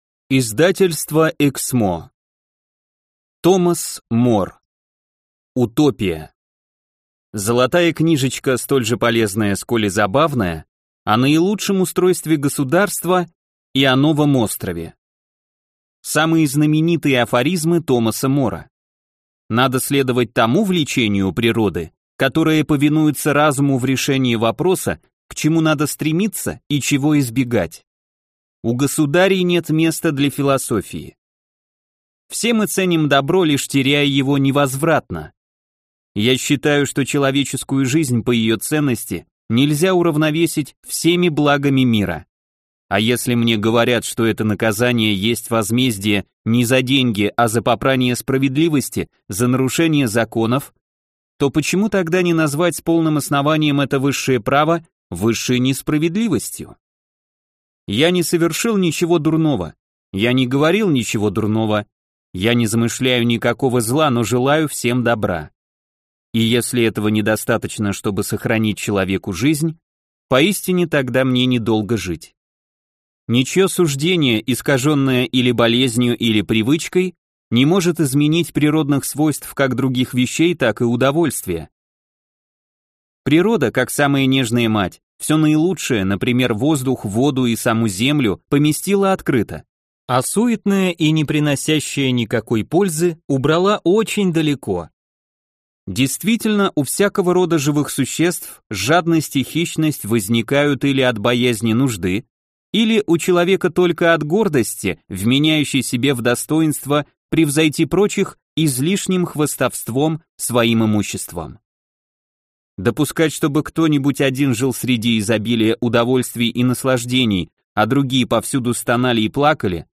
Аудиокнига Утопия | Библиотека аудиокниг